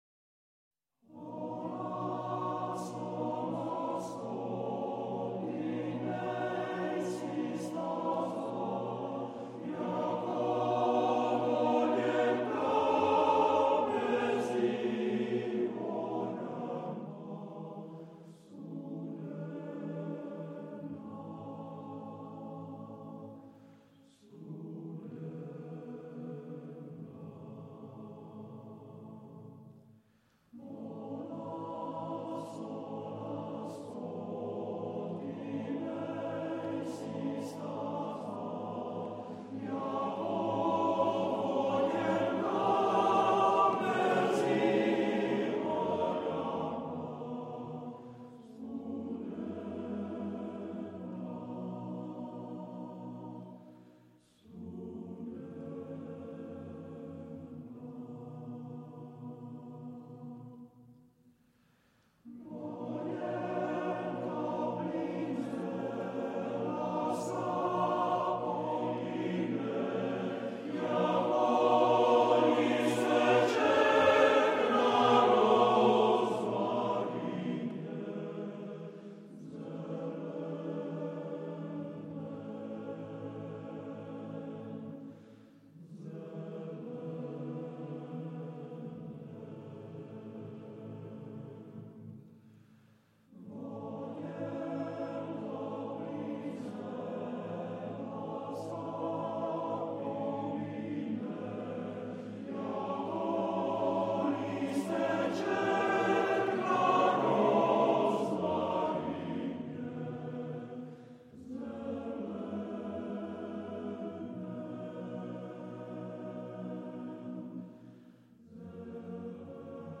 FF:VH_15b Collegium male choir